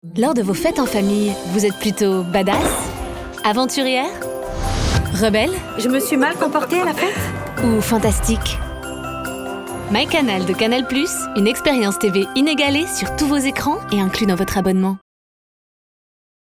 Voix Off - Bande Annonce - Canal +